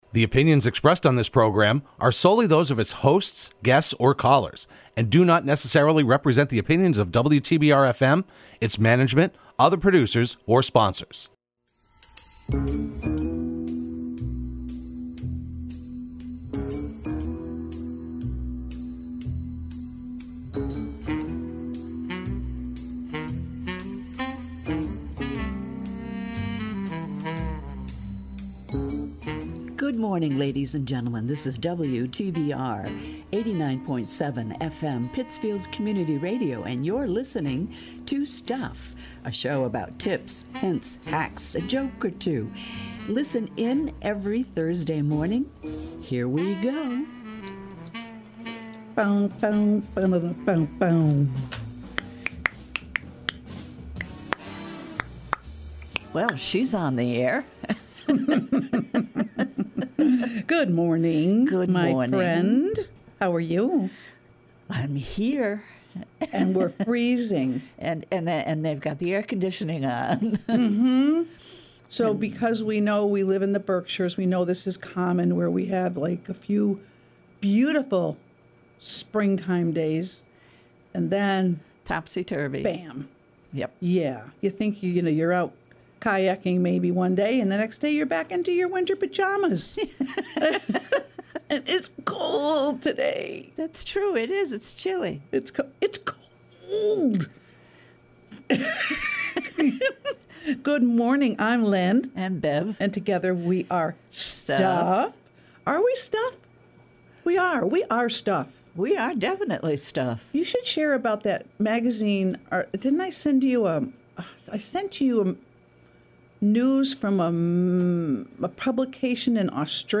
Broadcast every Thursday morning at 8:30am on WTBR.